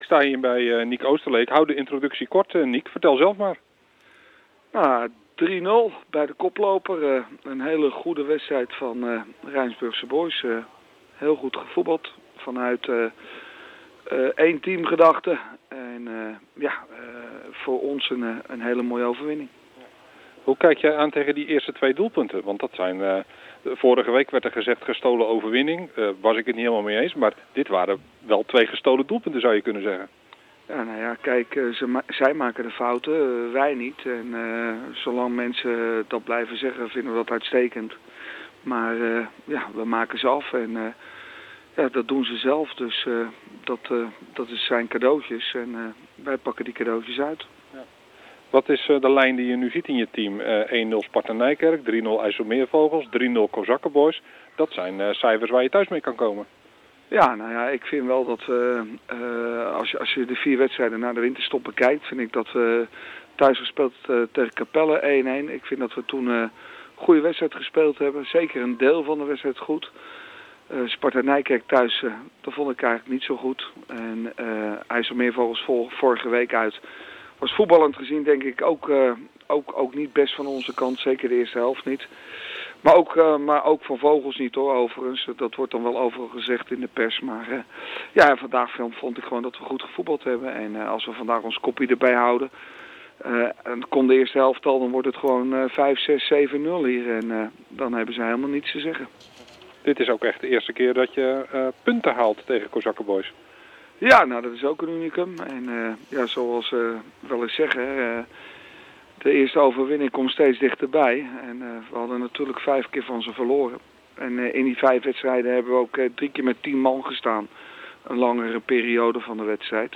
AUDIO: Interview